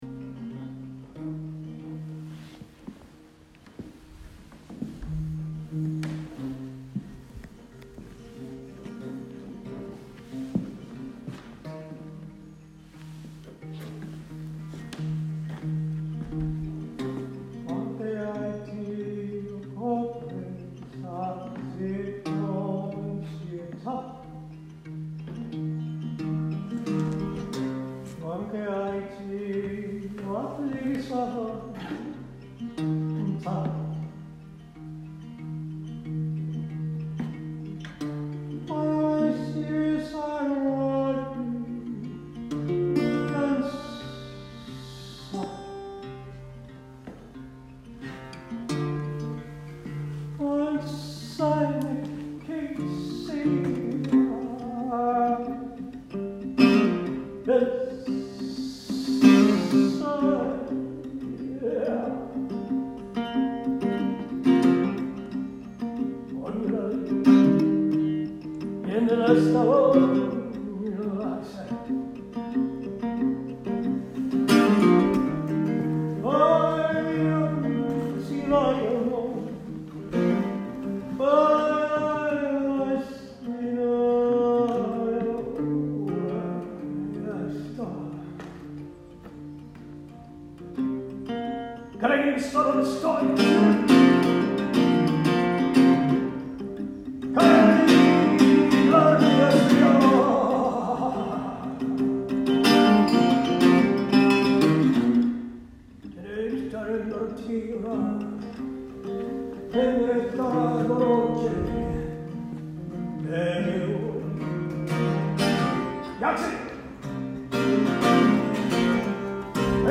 • Mise en voix et violon du vin de Château Maison blanche 2018